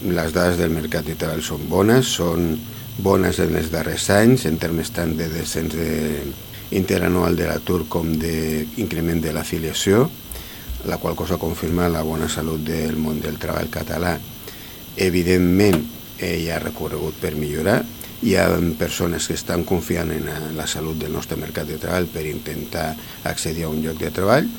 En qualsevol cas, el secretari de treball, Paco Ramos, treu ferro a la situació i fa una bona valoració de les dades.